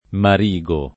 marigo [